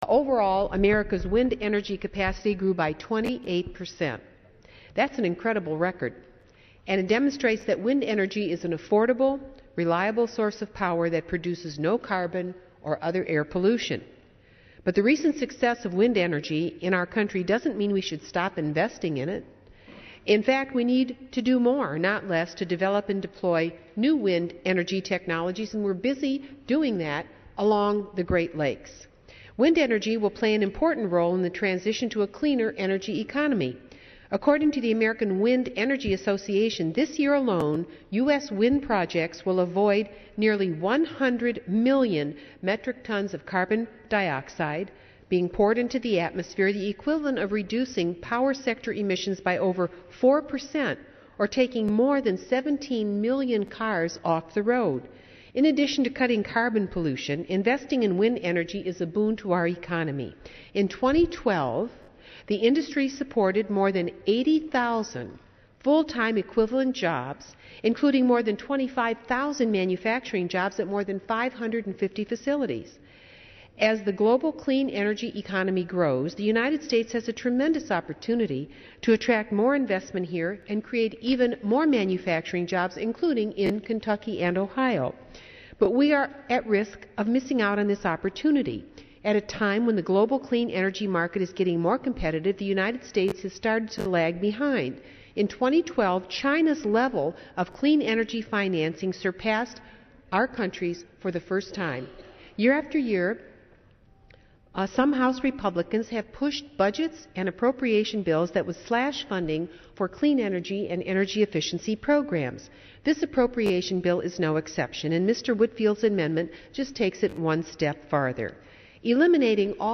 One example of the debate that went on included Rep. Marcy Kaptur, a Democrat from Ohio, rising to oppose an amendment that would have slashed federal money for wind energy projects.